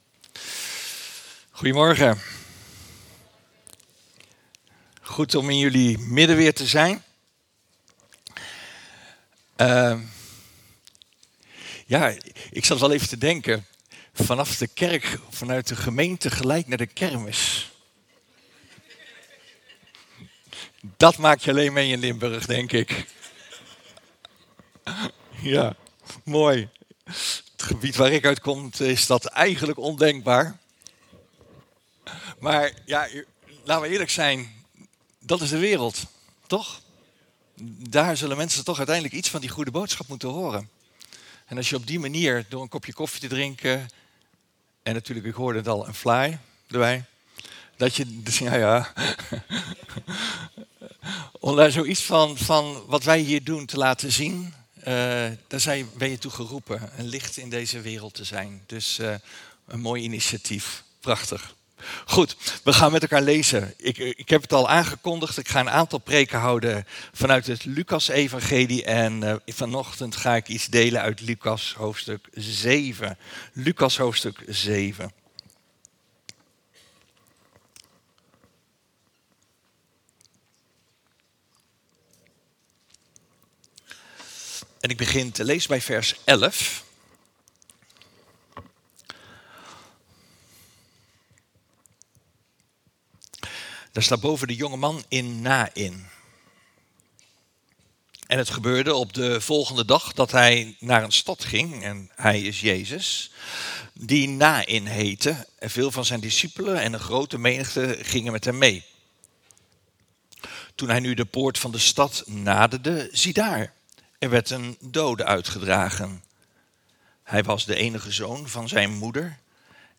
Passage: Lucas 7:11-17 Dienstsoort: Eredienst « De vreze des HEEREN